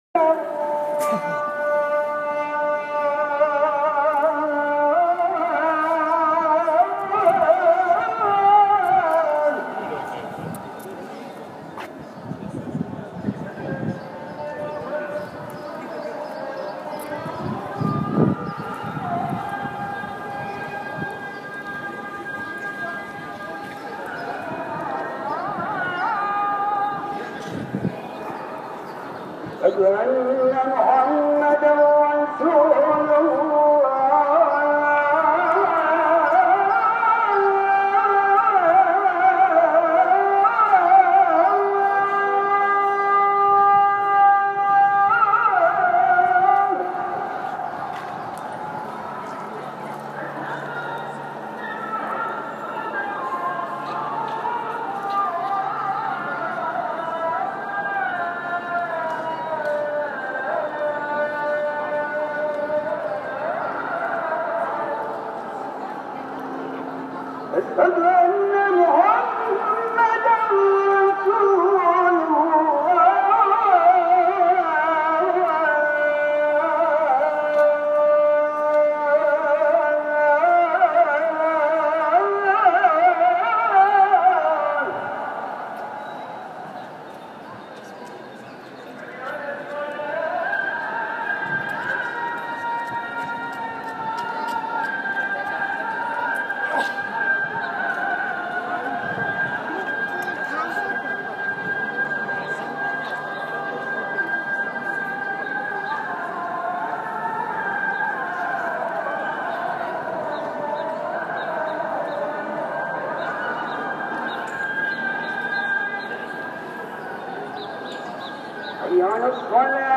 Call for Prayers
The first recording is the mid-day call and was recorded near the Hagia Sophia mosque.
There seems to be coordination between the 3 mosques as only 1 of the 3 will be broadcasting at a time during the 2-6 minute calls.
Call-For-Prayors.m4a